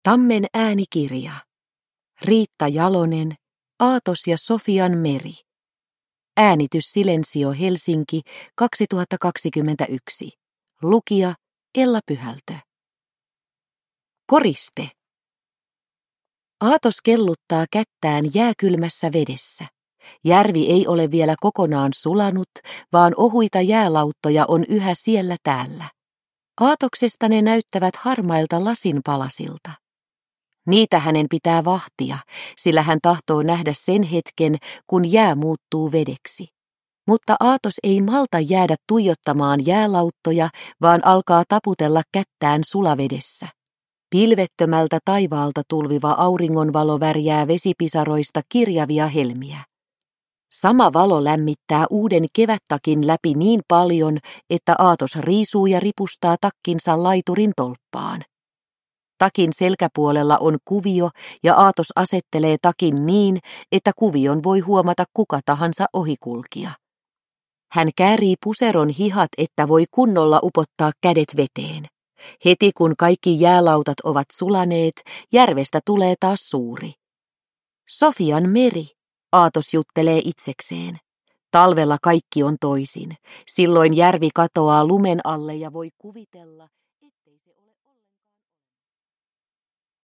Aatos ja Sofian meri – Ljudbok – Laddas ner